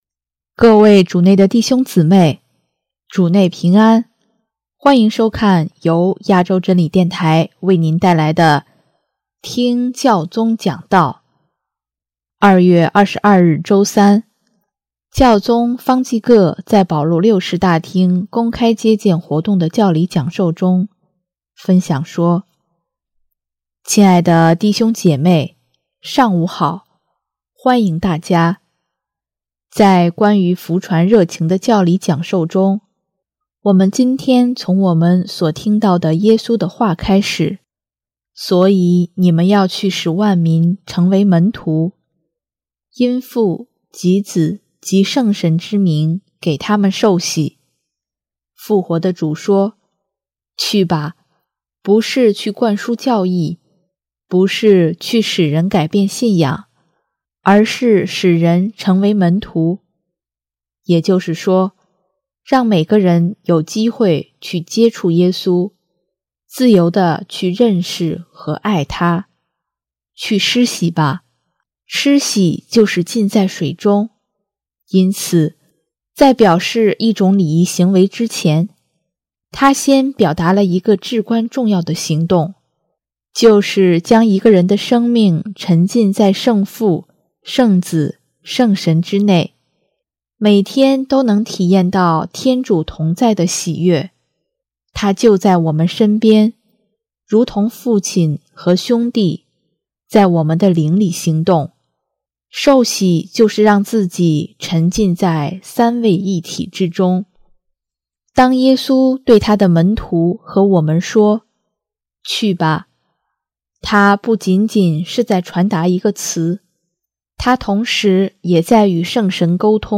2月22日周三，教宗方济各在保禄六世大厅公开接见活动的教理讲授中，分享说：